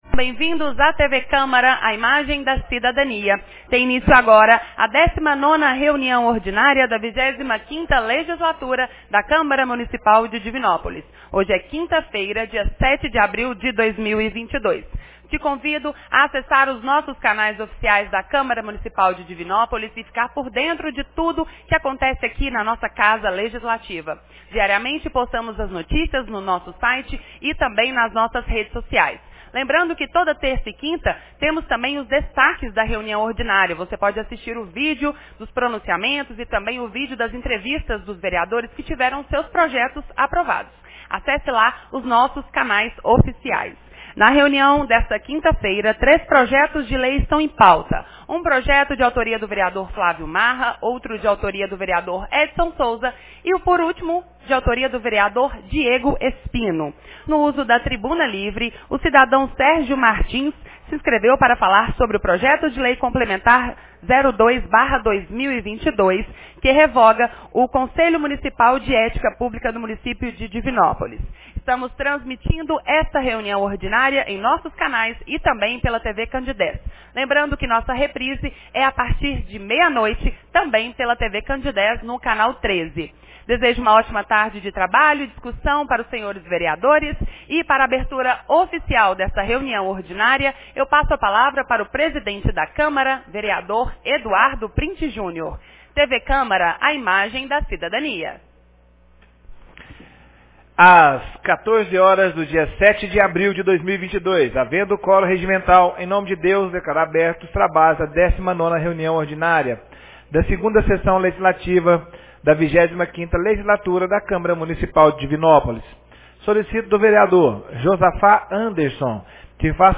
19ª Reunião Ordinária 07 de abril de 2022